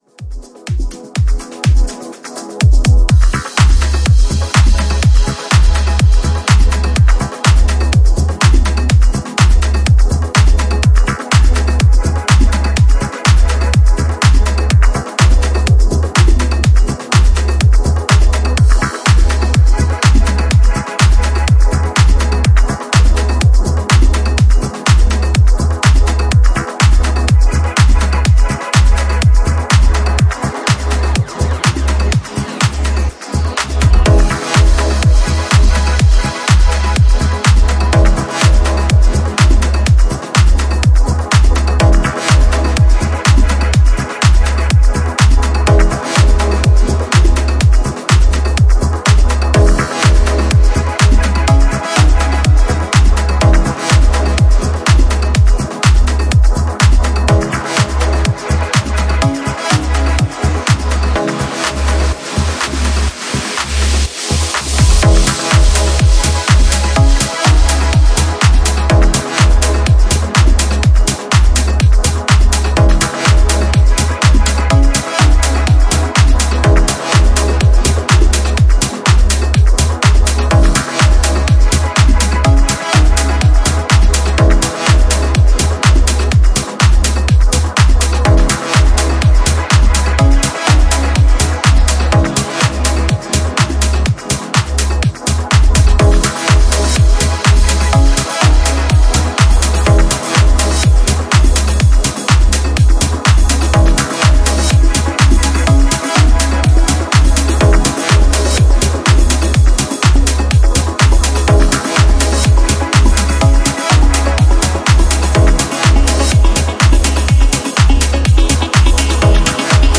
Genre: Melodic House